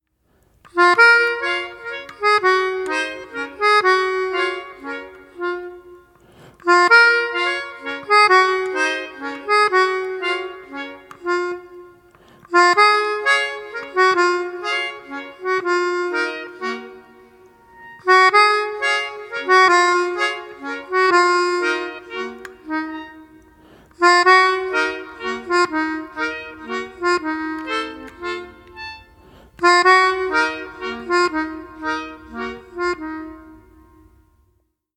• Качество: 170, Stereo
без слов
инструментальные
Гармошка